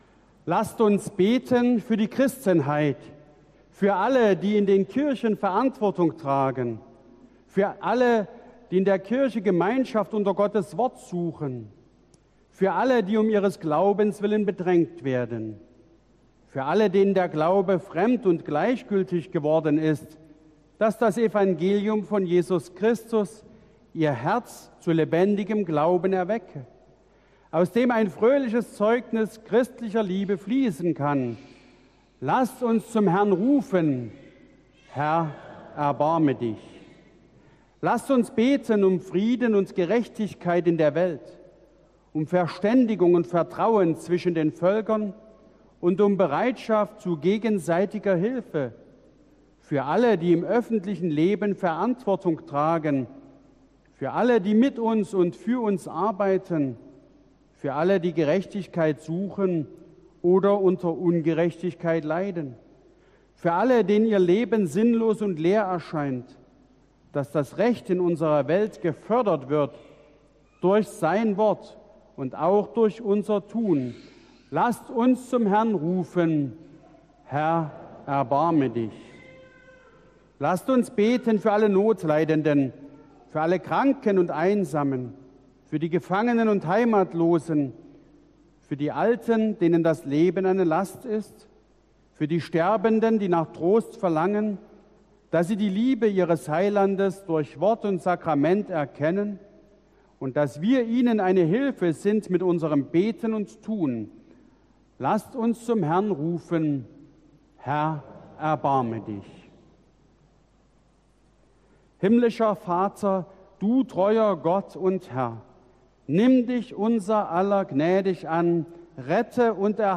Audiomitschnitt unseres Gottesdienstes vom 16. Sonntag nach Trinitatis 2022.